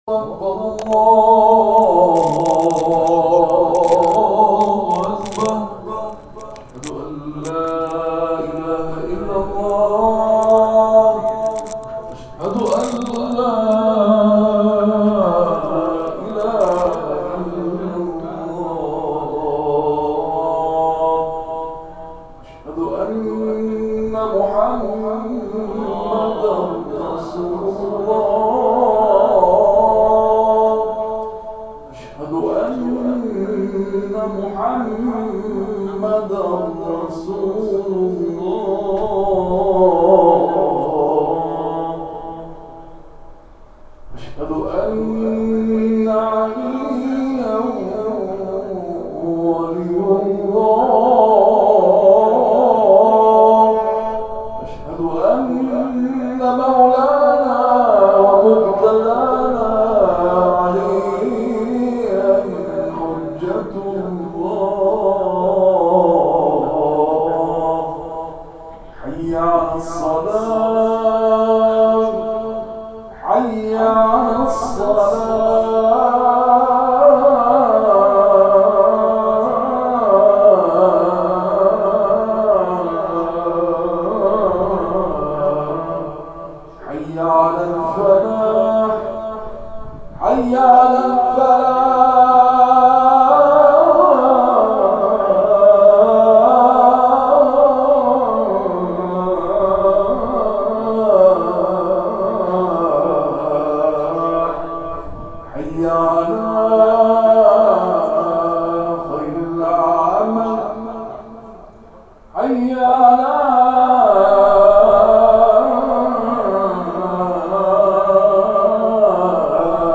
اذان